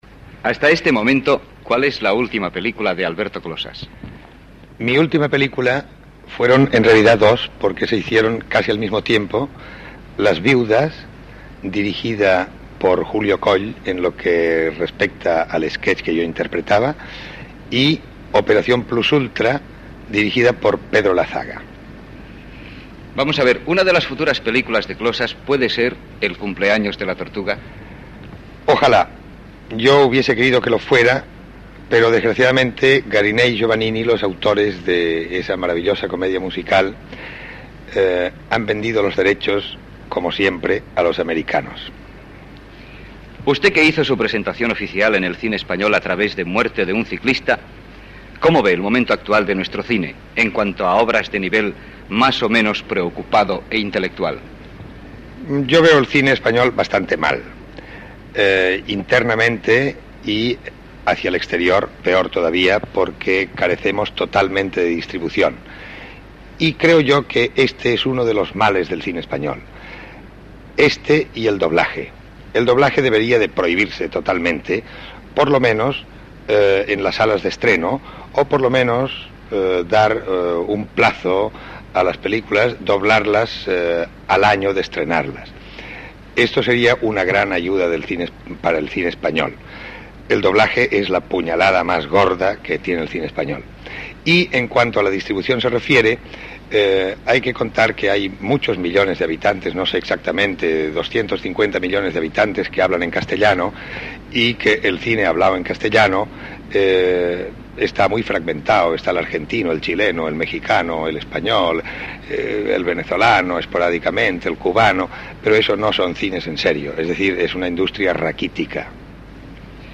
Entrevista a l'actor Alberto Closas sobre les seves últimes pel·lícules i la seva visió de la indústria cinematogràfica espanyola.
Entreteniment